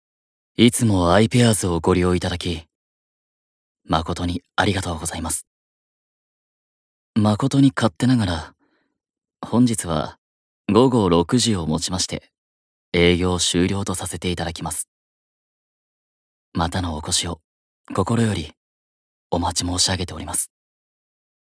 演技版